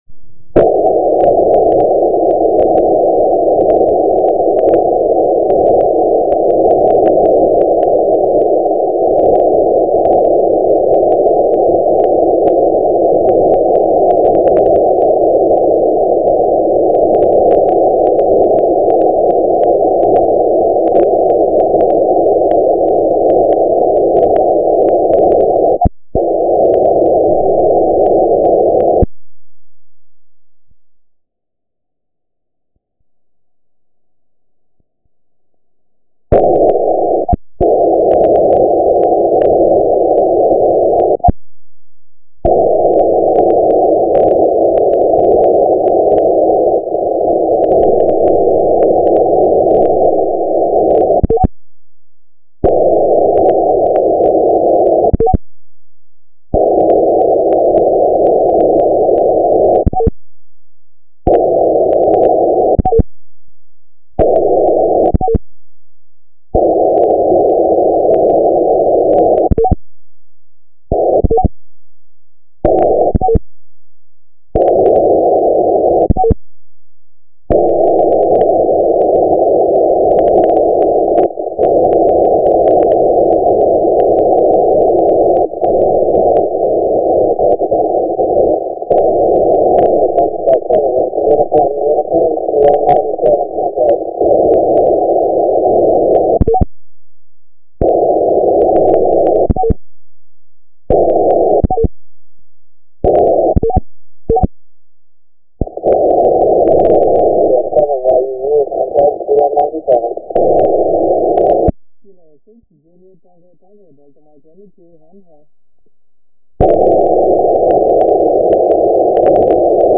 Here are some recordings of the contacts that I have made true satellites.